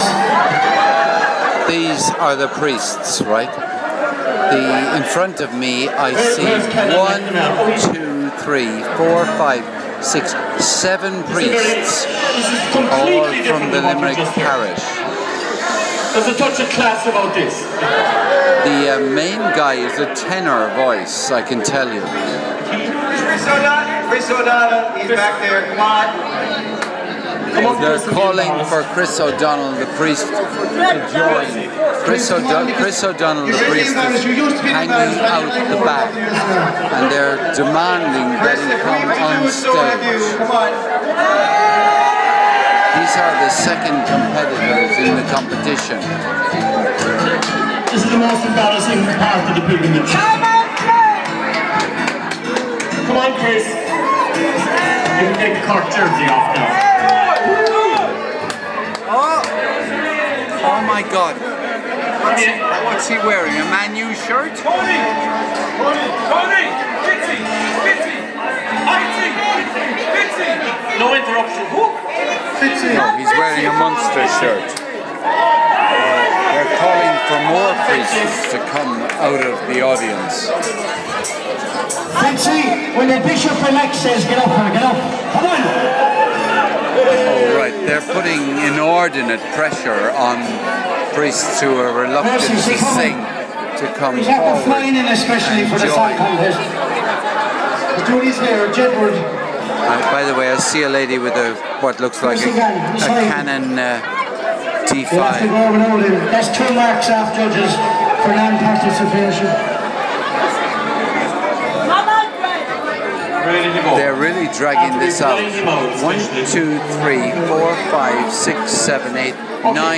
7 Priests from Limerick compete in Lourdes Talent Competition